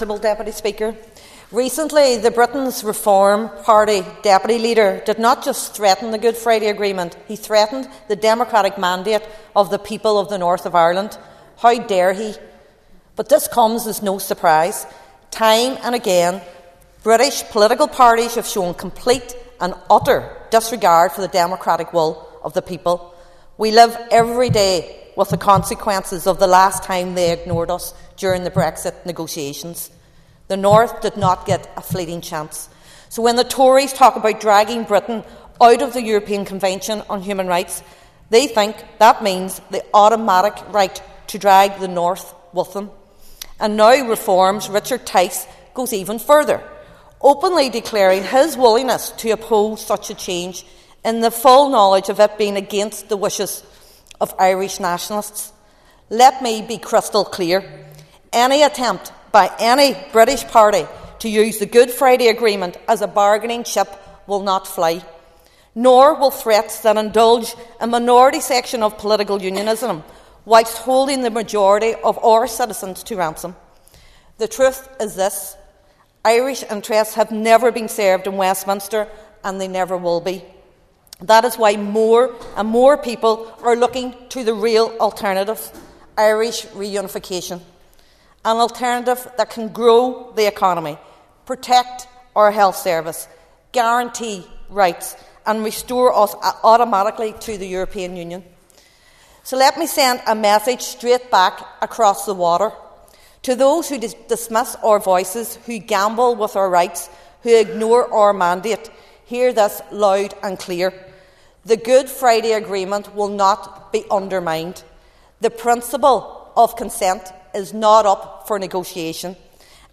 The Stormont Assembly has been told that only the people of Ireland can decide the North’s constitutional future.
Ms Ferguson told the Assembly this is not the case, and represents yet another example of parties in London ignoring the express wishes of the people of Northern Ireland.